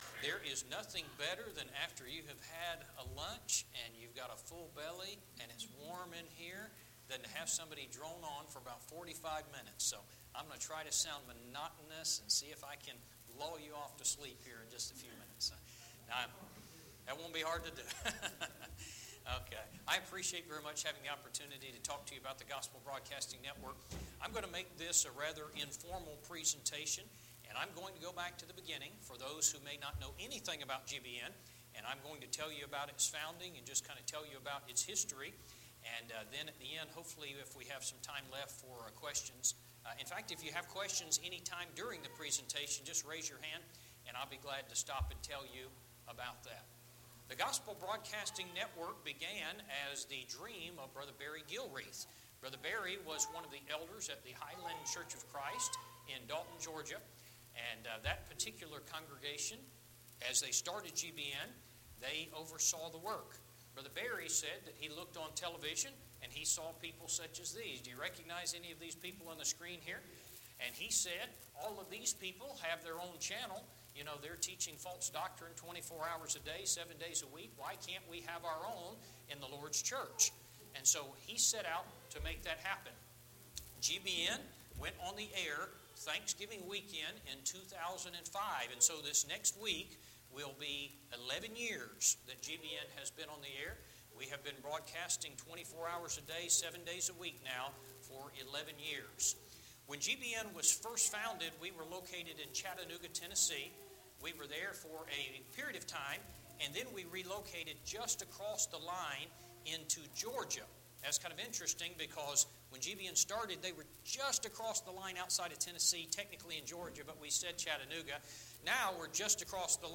2016 Fall Gospel Meeting Service Type: Gospel Meeting Preacher